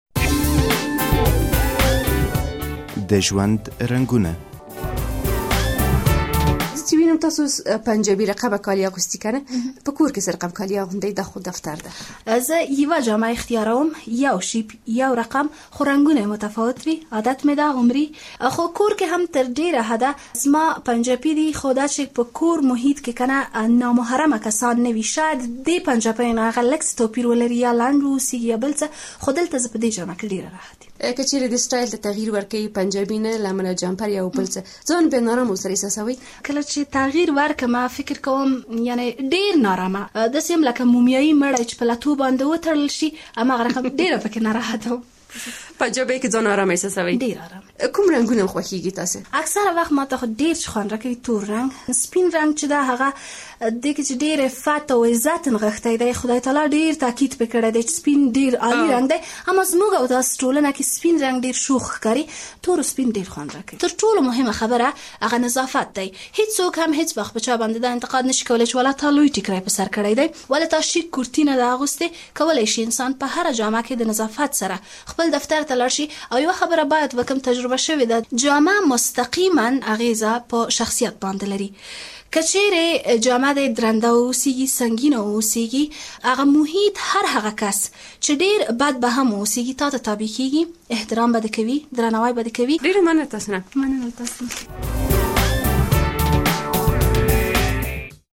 له يوې ميرمنې سره د مناسبو جامو په اړه مرکه